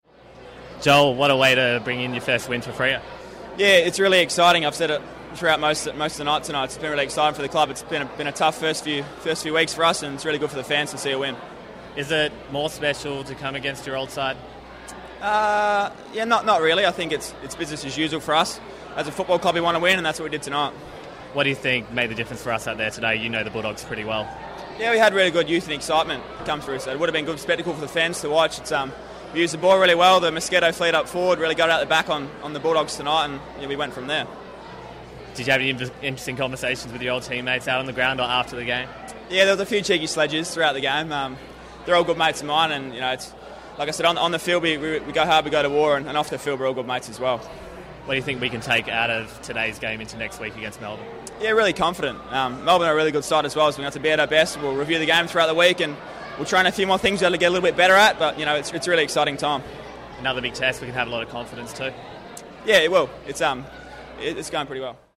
Joel Hamling chats to Docker TV after Freo's win over the Dogs.